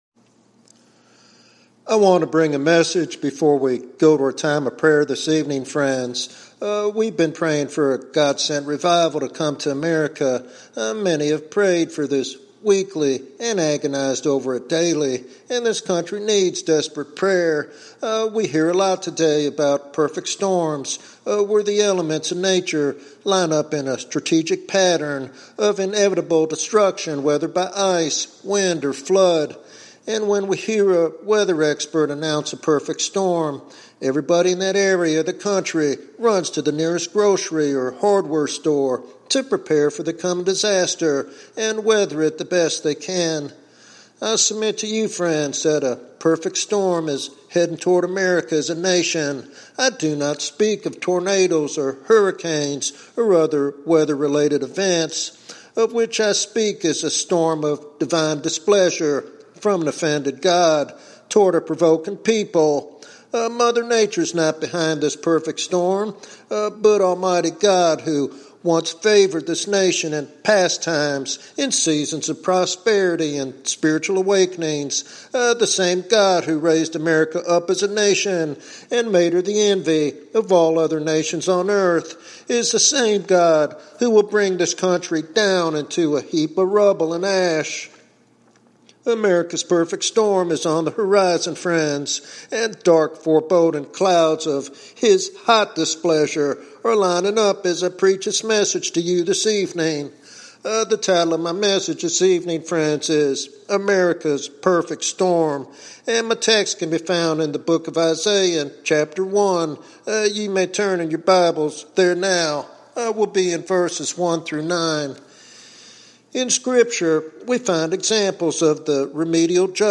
He challenges the church to awaken from compromise and calls the nation to humble itself before God to avert destruction. This sermon is a solemn call to prayer, repentance, and revival in the face of looming spiritual crisis.